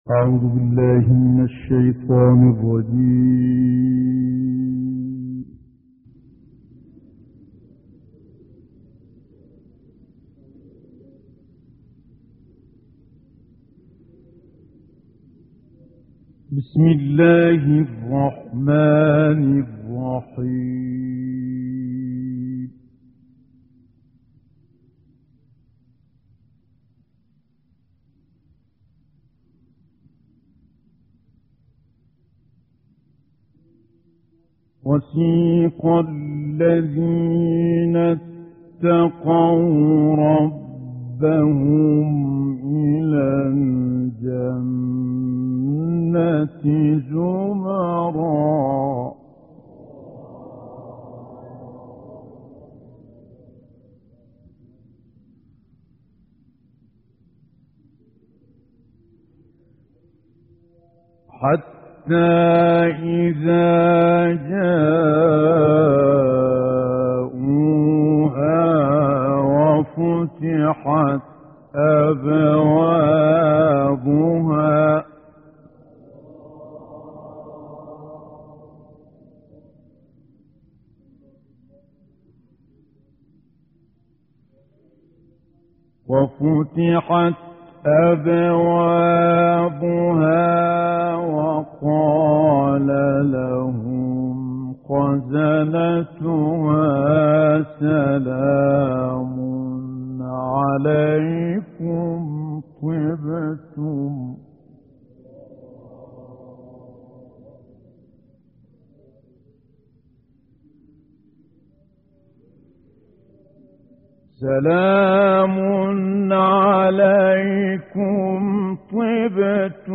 دانلود قرائت سوره های زمر آیات 73 تا آخر و سوره غافر آیات 1 تا 3 - استاد متولی عبدالعال :: پایگاه فرهنگی مذهبی قرآن و عترت